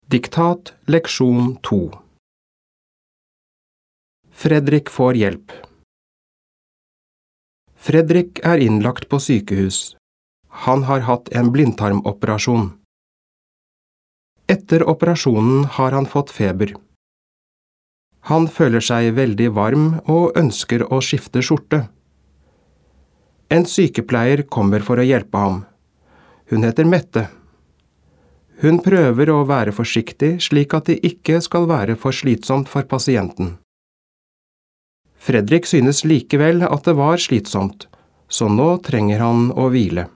Diktat leksjon 2
• Første gang leses hele teksten, og du skal bare lytte.